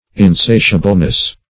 Search Result for " insatiableness" : The Collaborative International Dictionary of English v.0.48: Insatiableness \In*sa"tia*ble*ness\, n. Greediness of appetite that can not be satisfied or appeased; insatiability.